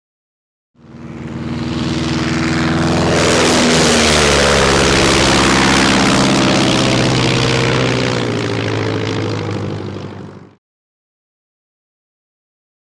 Bi Plane|Ext|Fly By | Sneak On The Lot
Prop Plane; Fly By; Bi Plane Low Fly Over.